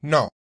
Ääntäminen
Tuntematon aksentti: IPA : /nɒt/ IPA : /nɑt/